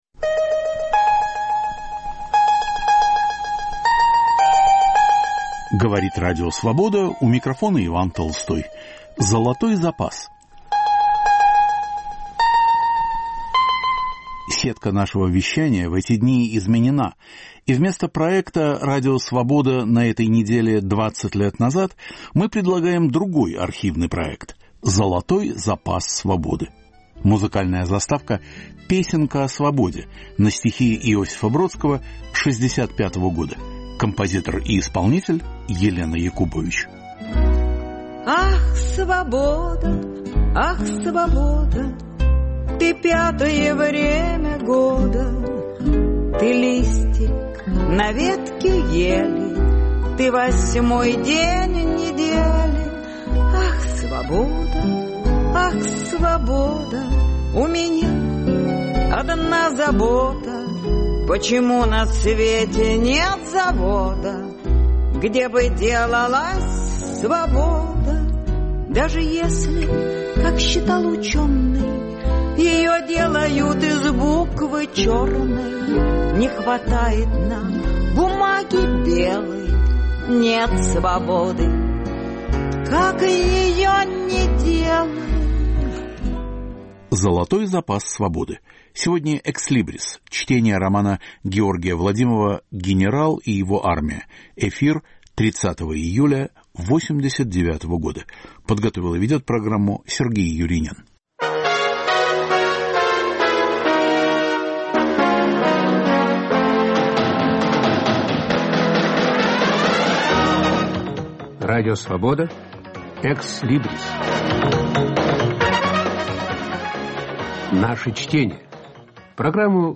Золотой запас "Свободы". Ex libris. Чтение романа Георгия Владимова "Генерал и его армия"
Роман о Великой Отечественной войне. Читает Юлиан Панич. В студии Радио Свобода автор - Георгий Владимов.